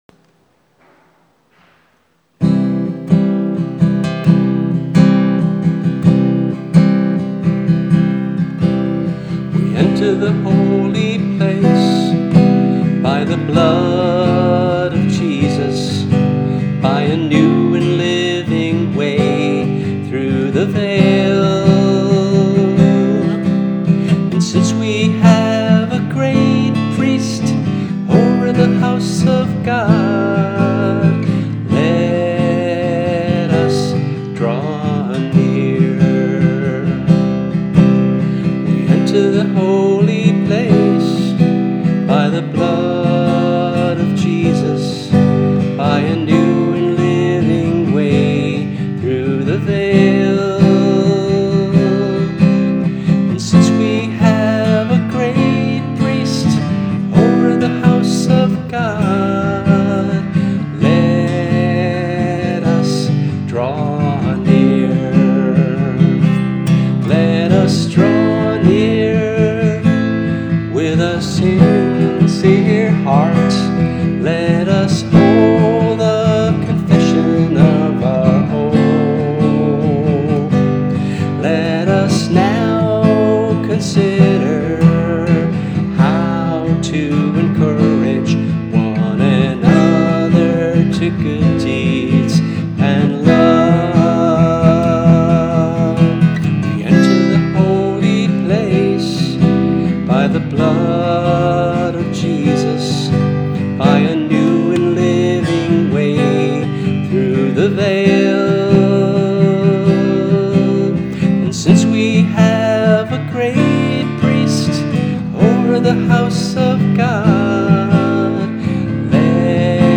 (with guitar)